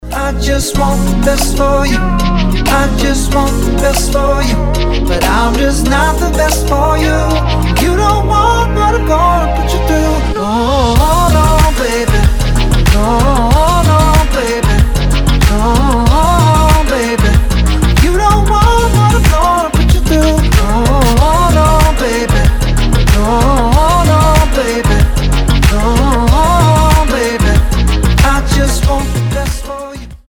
• Качество: 320, Stereo
поп
мужской вокал
dance